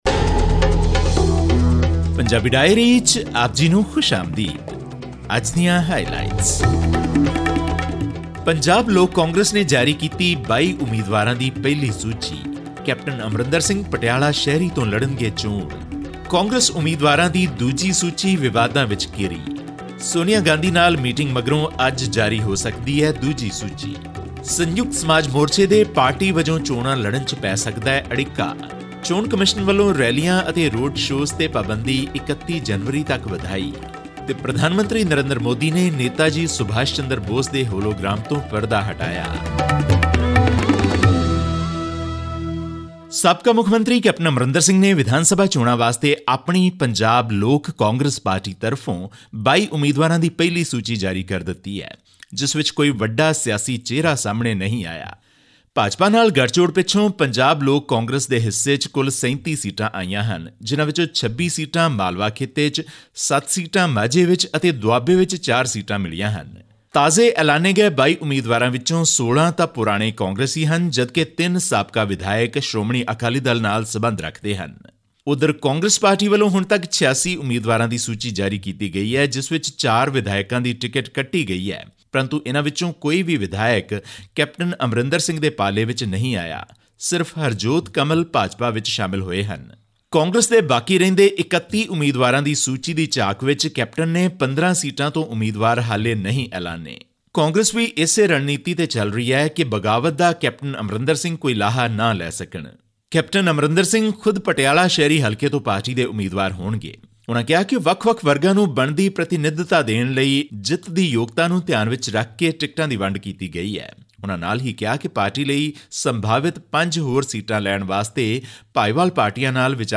All this and more in our weekly news segment from India.